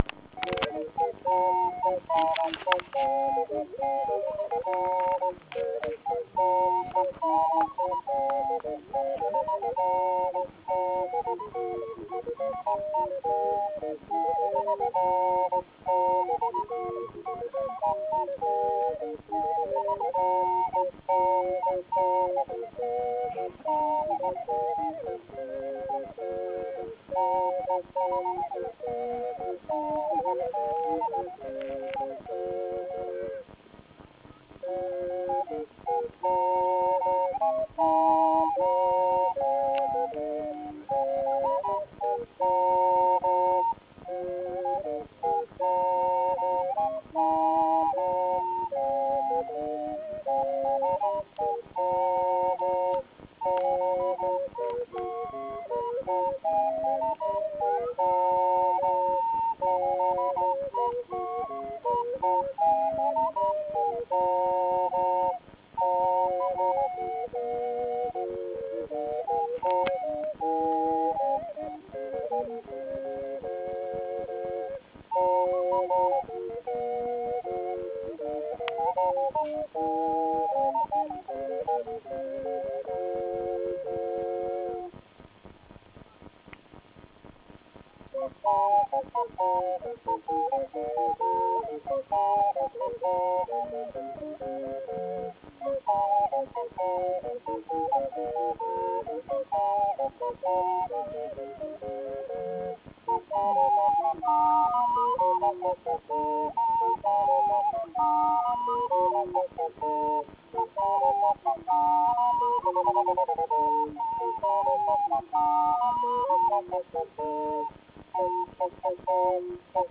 Concerto al rifugio Chiggiato
I loro strumenti caratteristici sono dei corni lunghi oltre 4 metri e costruiti da loro col legno di abete dei boschi della Turignia. Anche gli zufoli sono stati costruiti da loro.
Scarica breve brano musicale del Coro che suona gli zufoli
flauti.WAV